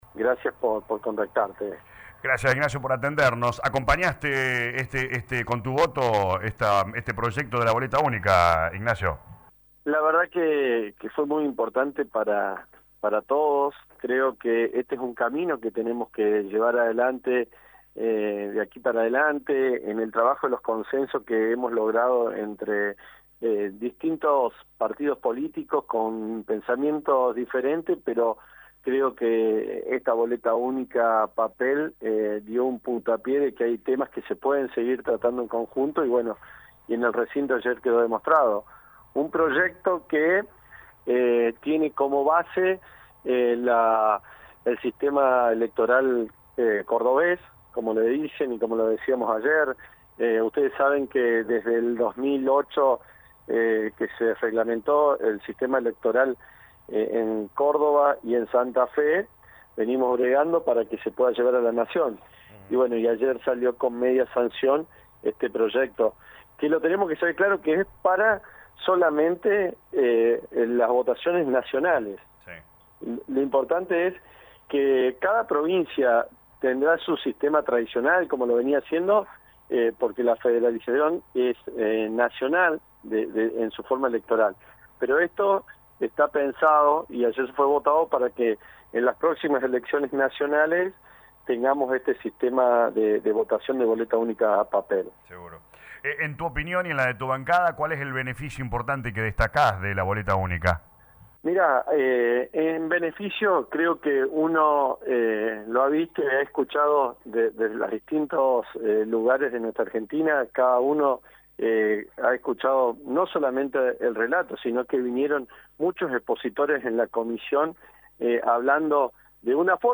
En diálogo con LA RADIO 102.9 FM el diputado Nacional Ignacio García Aresca afirmó que esta boleta única en papel dio el puntapié que se necesitaba para demostrar de que es un tema que se puede trabajar en conjunto y así ha quedado demostrado.